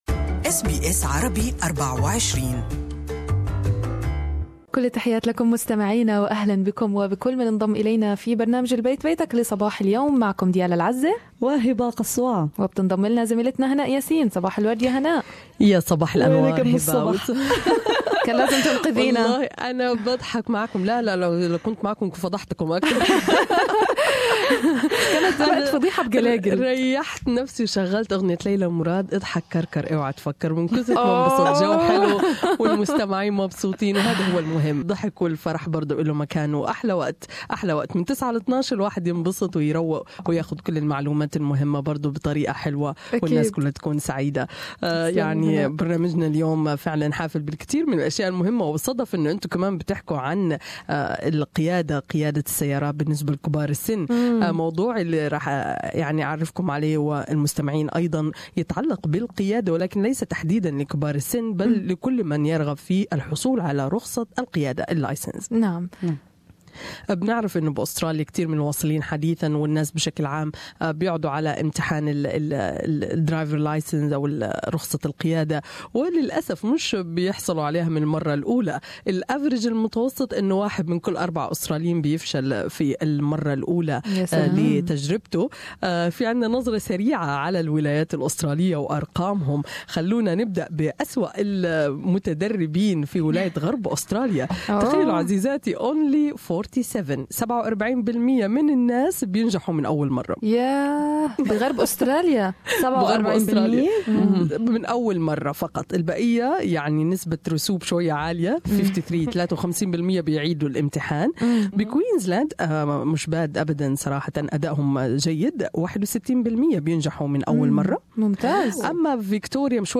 المزيد في اللقاء اعلاه استمعوا هنا الى البث المباشر لاذاعتنا و لاذاعة BBC أيضا حمّل تطبيق أس بي أس الجديد على الأندرويد والآيفون للإستماع لبرامجكم المفضلة باللغة العربية.